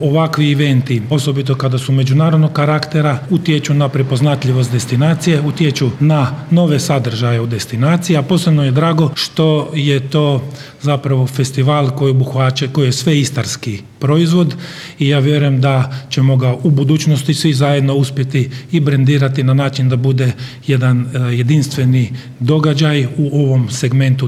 ROVINJ - Drugi GinIstra Festival sve je bliže pa je tim povodom ovog utorka u Rovinju održana konferencija za medije na kojoj je predstavljeno ovogodišnje izdanje Festivala, a koje će se upravo u Rovinju, u Staroj tvornici duhana, održati ovog vikenda - u petak i subotu.
Gradonačelnik Rovinja Marko Paliaga istaknuo je značaj održavanja ovakvih manifestacija.